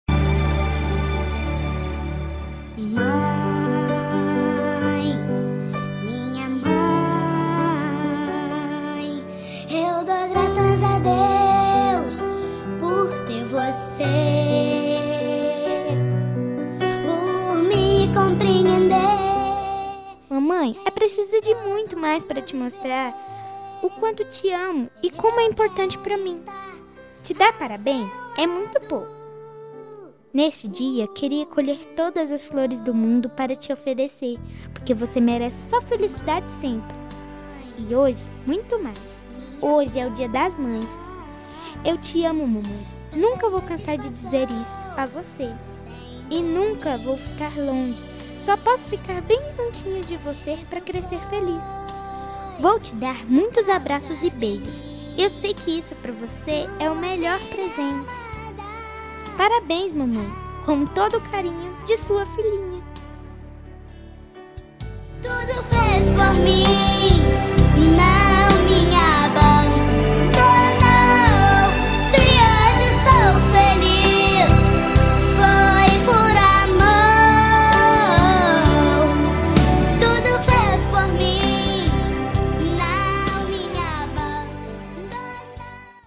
Voz Menina